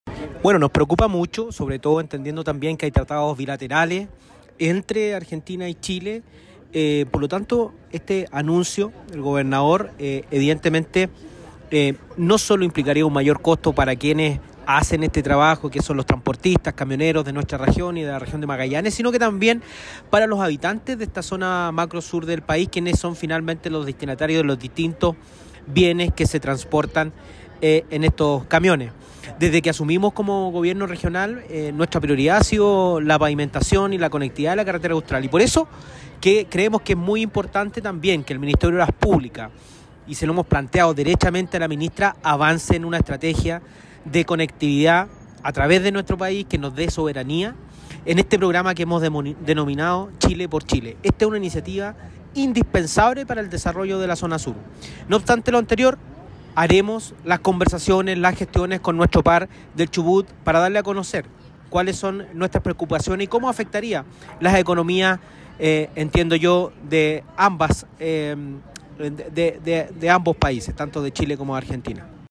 Es el caso del gobernador de Aysén, Marcelo Santana, quien evaluó con profunda preocupación las declaraciones de la autoridad de la provincia del Chubut, en orden a gravar con este impuesto, a los camioneros chilenos.